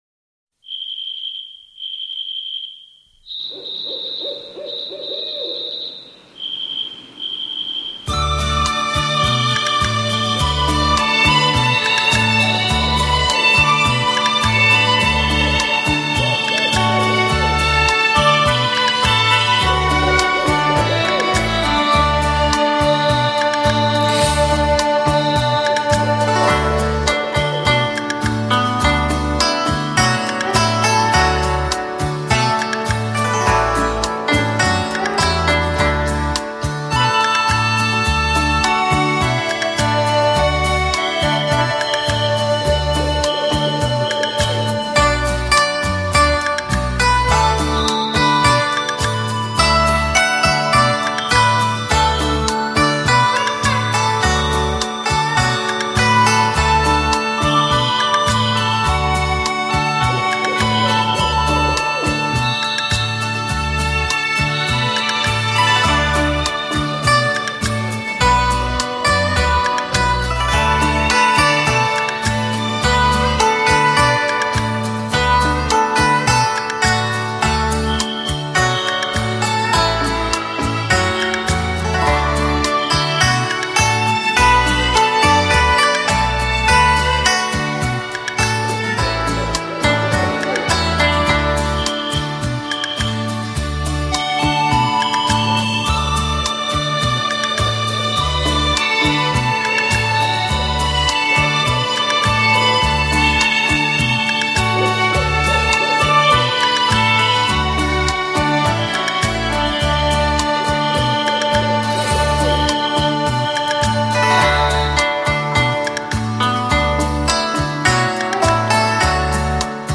古筝婉约 琴音隽永